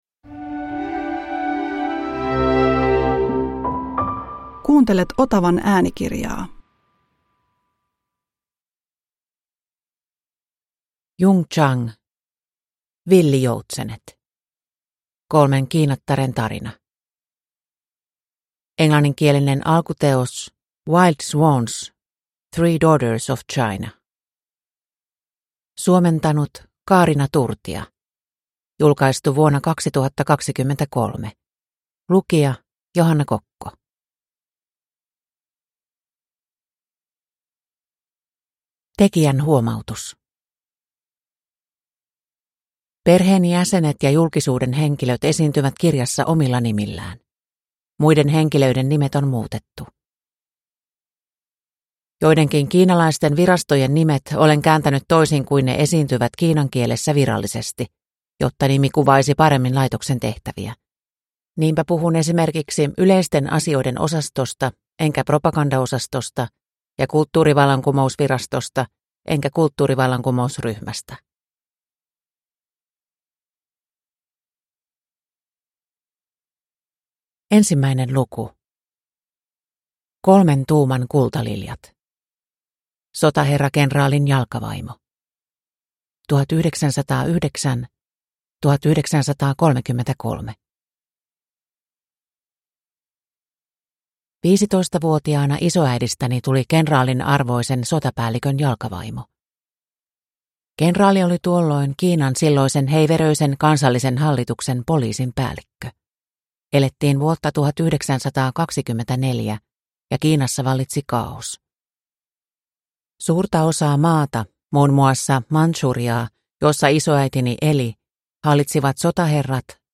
Villijoutsenet – Ljudbok